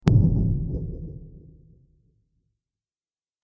دانلود آهنگ تصادف 6 از افکت صوتی حمل و نقل
جلوه های صوتی
دانلود صدای تصادف 6 از ساعد نیوز با لینک مستقیم و کیفیت بالا